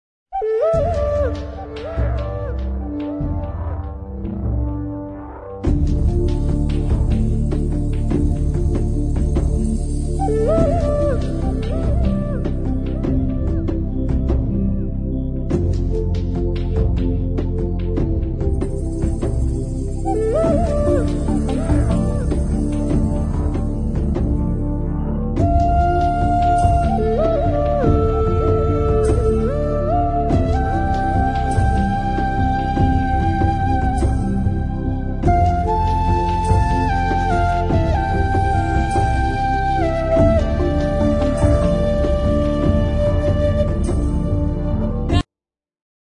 Это блок-флейта?
surprised ОМГ)) не, не блочка и не вистл... похожу что какая-то хитрая этническая дудка. Ну или может быть блочка... басовая biggrin